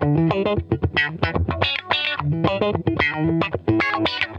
FUNK-E 1.wav